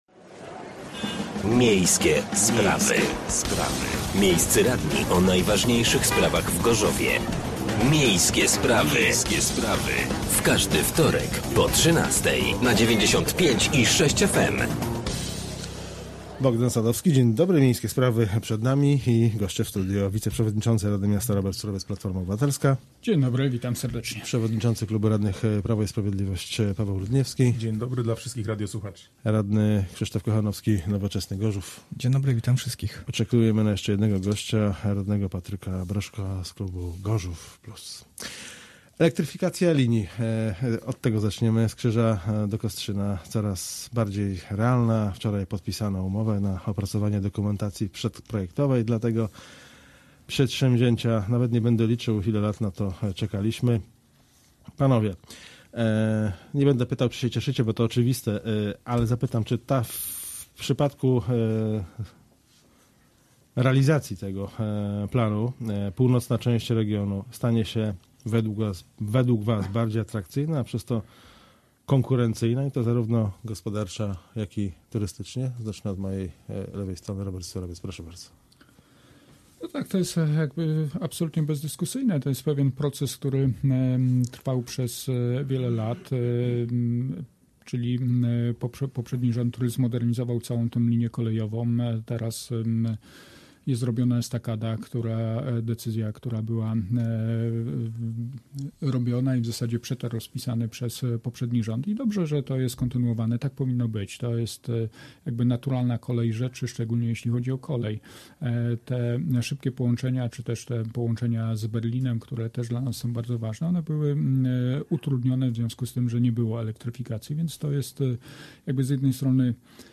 Gośćmi byli radni: Robert Surowiec (Platforma Obywatelska), Paweł Ludniewski (Prawo i Sprawiedliwość), Patryk Broszko (Gorzów Plus) i Krzysztof Kochanowski (Nowoczesny Gorzów)